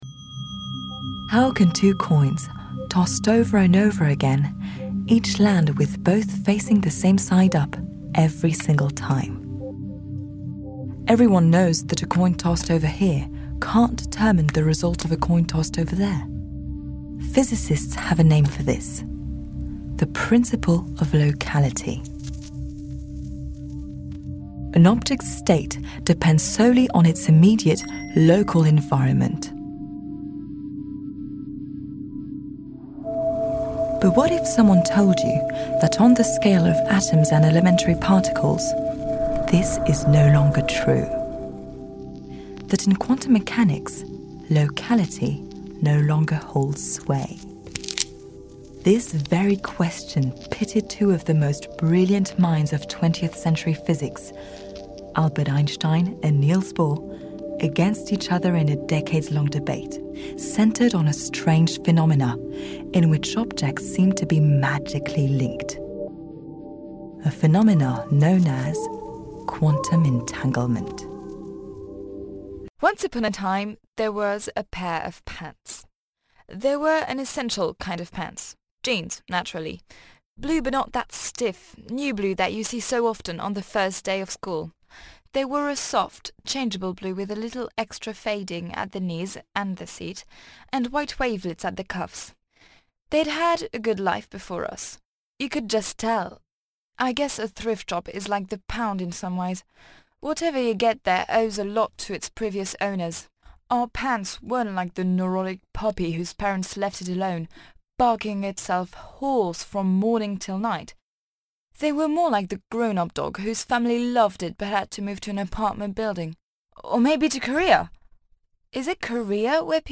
BANDE DÉMO - ANGLAIS